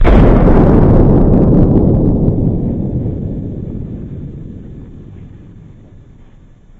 遥远的爆炸
描述：遥远的爆炸。
标签： 战斗 战争 爆炸
声道立体声